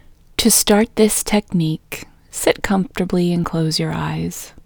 Quietness-Female-1.mp3